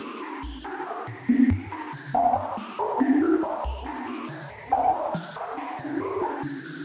STK_MovingNoiseD-140_01.wav